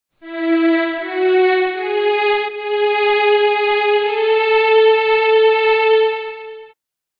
In his adagio sostenuto, Rachmaninov insists on G sharp, and the limits of the phrase emphasize the impression of an interval between E (lower note) and G sharp (thus emphasized), which implies a tonic chord of E major (or maybe C sharp minor, as long as further analyzing the theme has not enabled us to choose between the two relative keys).